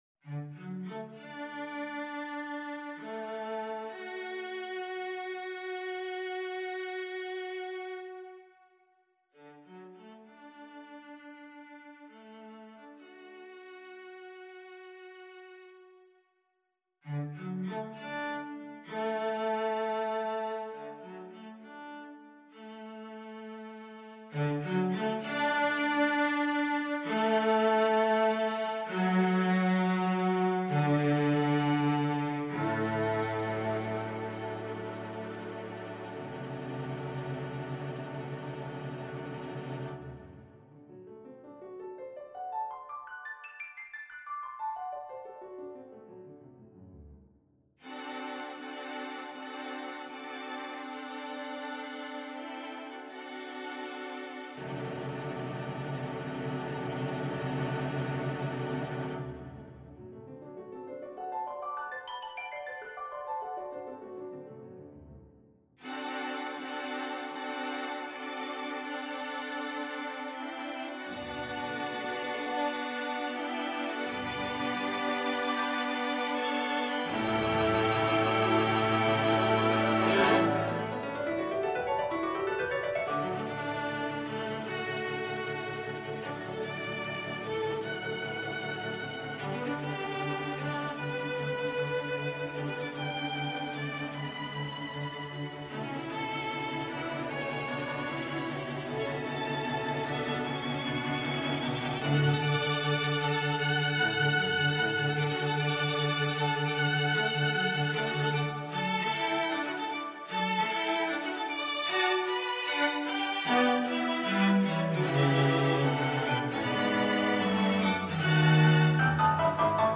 Saint-Saëns, Camille - Piano Concerto No.1, Op.17 Free Sheet music for Piano and Ensemble
Style: Classical